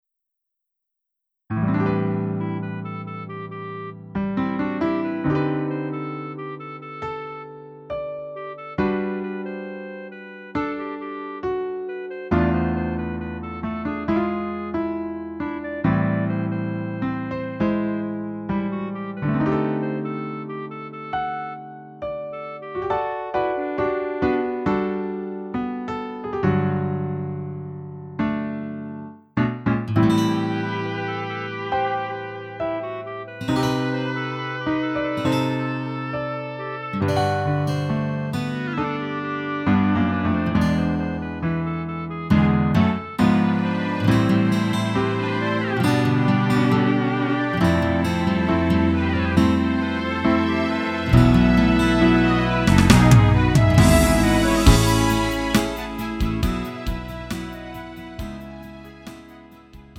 음정 원키 4:13
장르 가요 구분 Lite MR